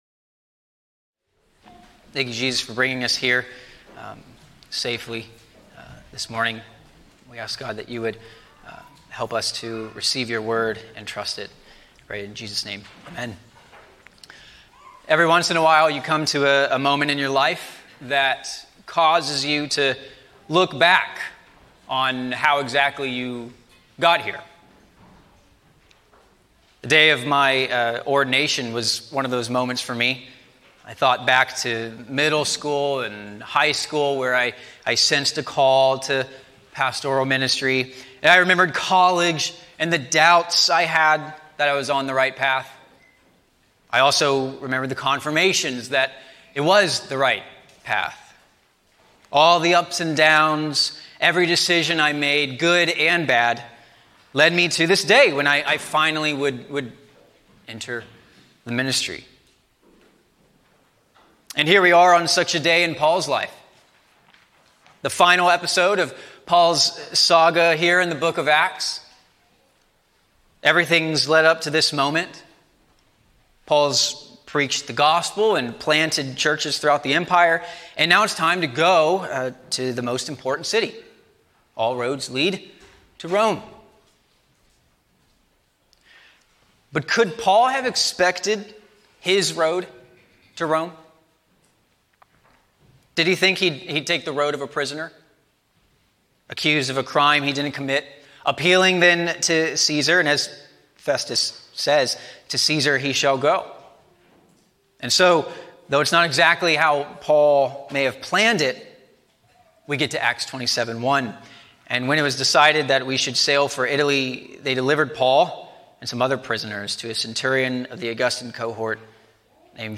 A sermon on Acts 27:1-26